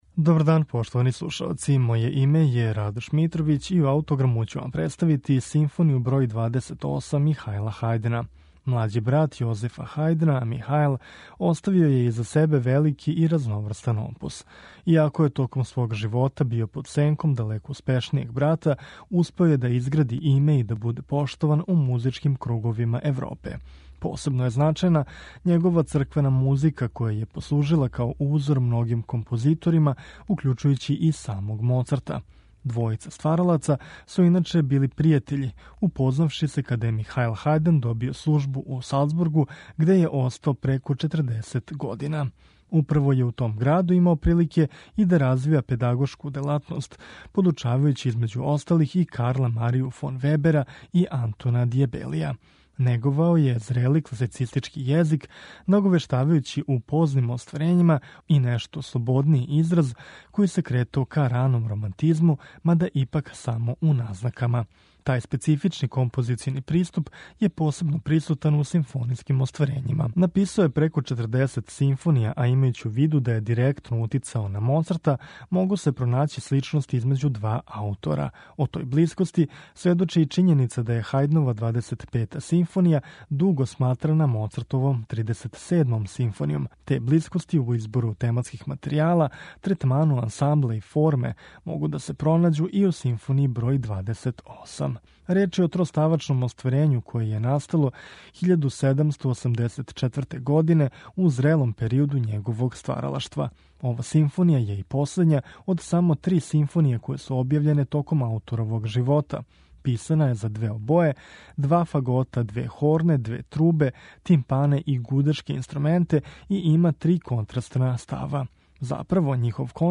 Посебно су била запажена његова симфонијска дела, која су инспирисала и Моцарта, а међу којима се издваја Симфонија број 28, коју ћемо представити у интерпретацији Словачког камерног оркестра, под управом Бохдана Вархала.